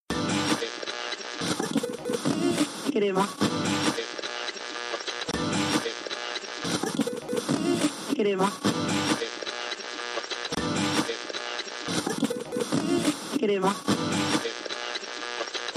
radionoise.a6a612be.mp3